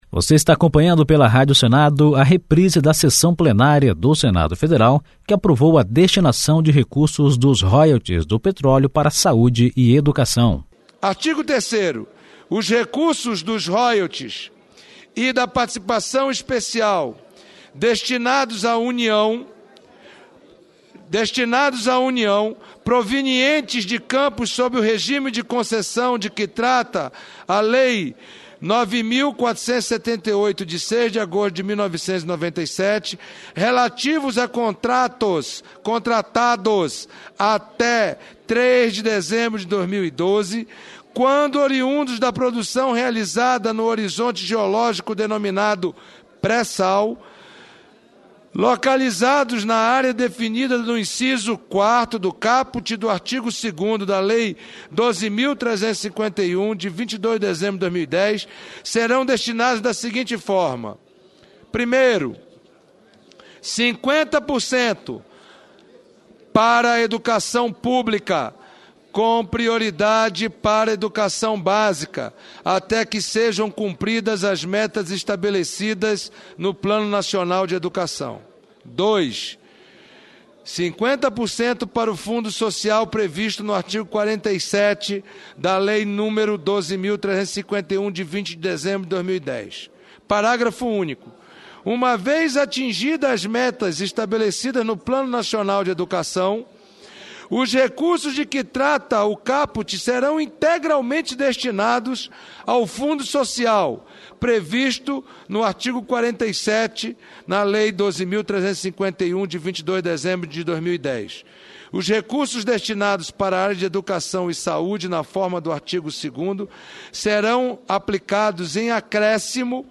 Sessão que aprovou destinação de royalties do petróleo para educação (3)